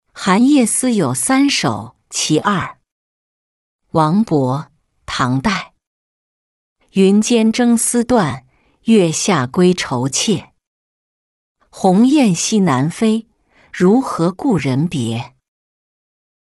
醉赠刘二十八使君-音频朗读